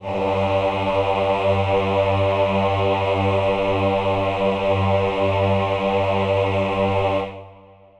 Choir Piano
G2.wav